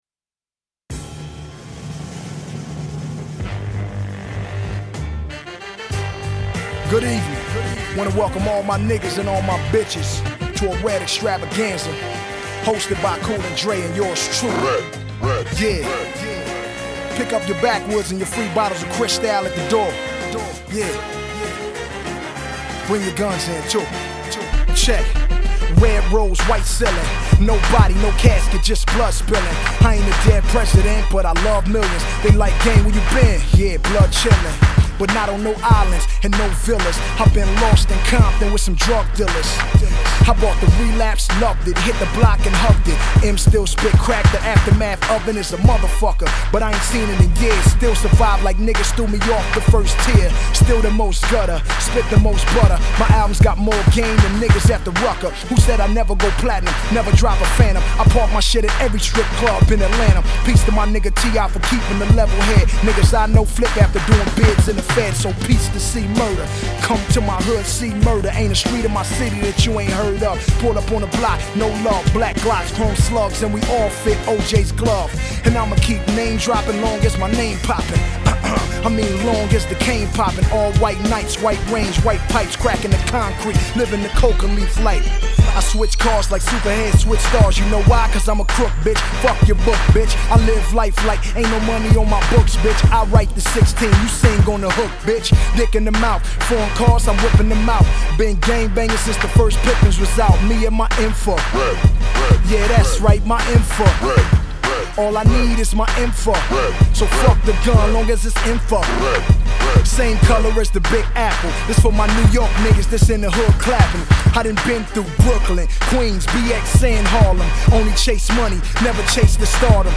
Blaxpoitation-infused production